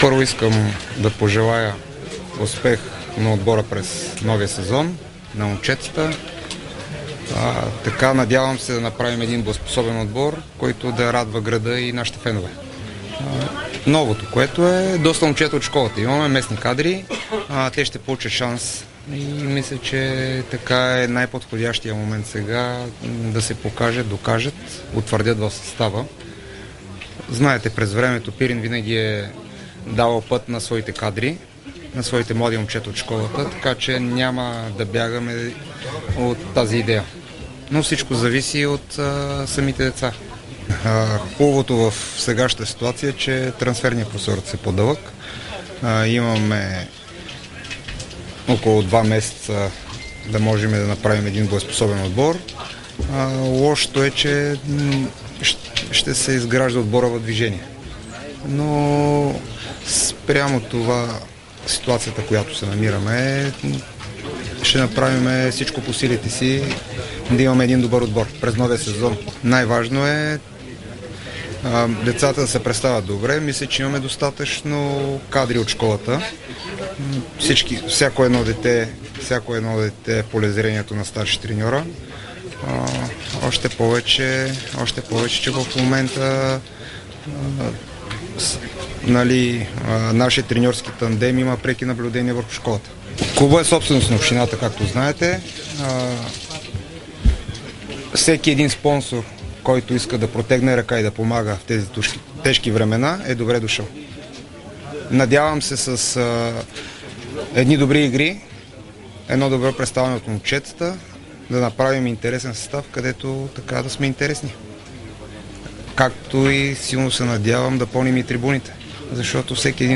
говори пред медиите преди старта на първата тренировка на отбора за новия сезон.